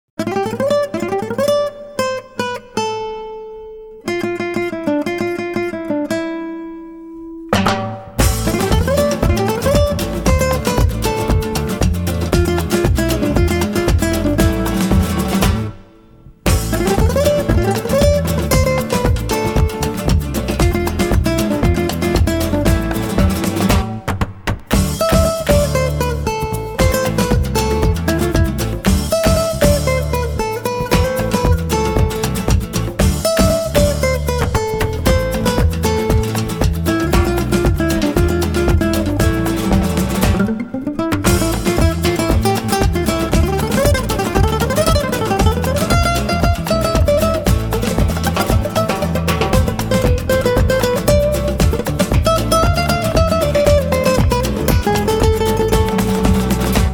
• Качество: 192, Stereo
гитара
без слов
инструментальные
испанская гитара
Фламенко